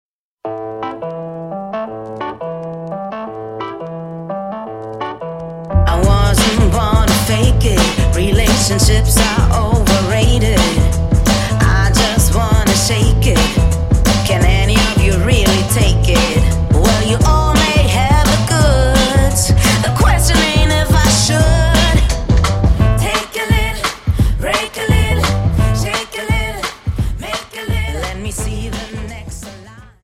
Dance: Jive 43 Song